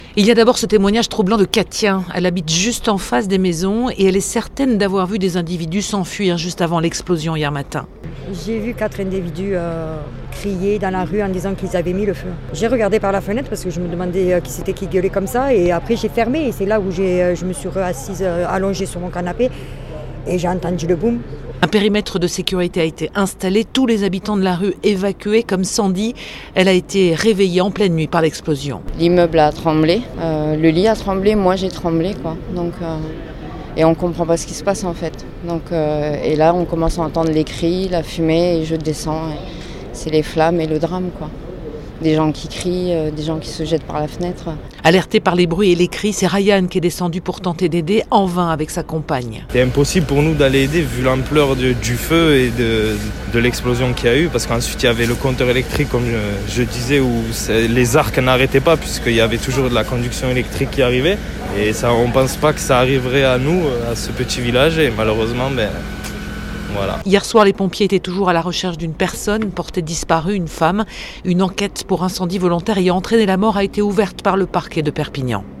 Reportage à Saint-Laurent-de-la-Salanque